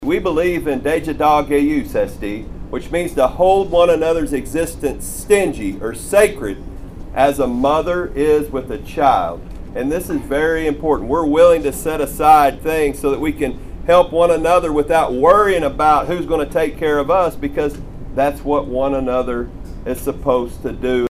Deputy Principal Chief Bryan Warner says the opening ties